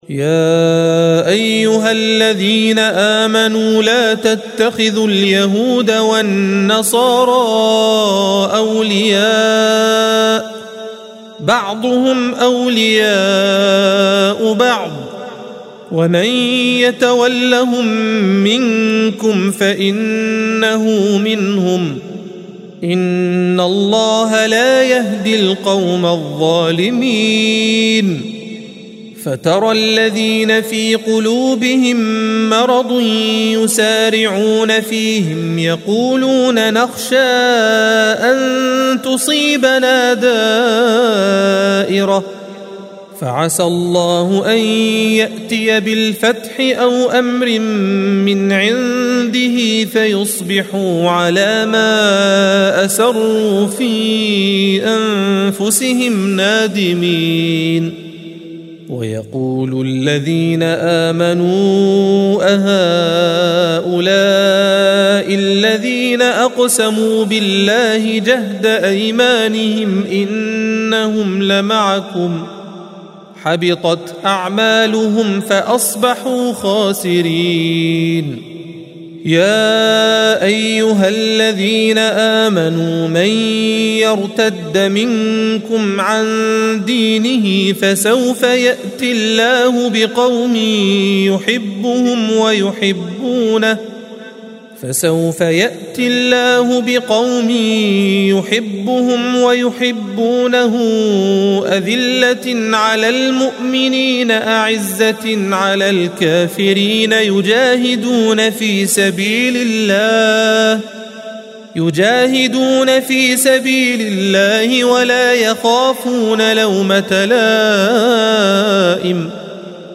الصفحة 117 - القارئ